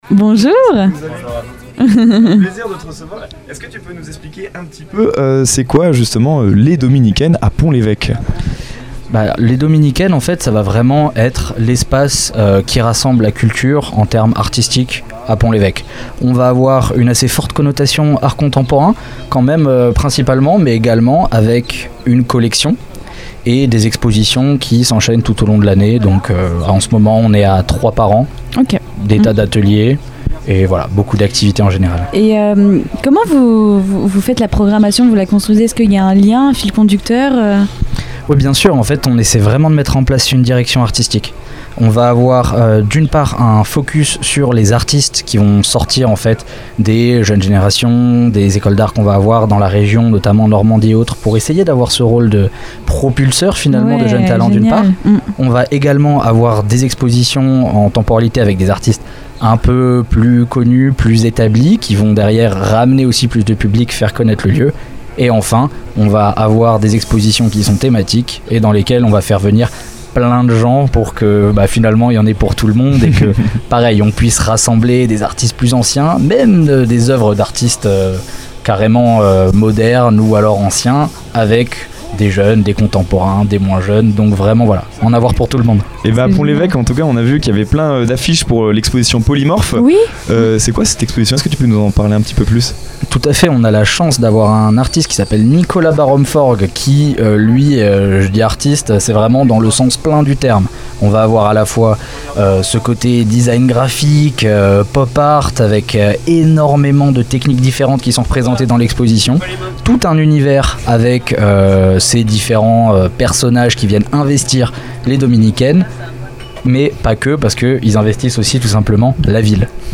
Un entretien passionnant qui met en lumière un espace vivant, à la croisée du patrimoine et de la création contemporaine, au cœur de la vie culturelle normande.